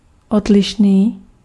Ääntäminen
Tuntematon aksentti: IPA: /ɔdlɪʃniː/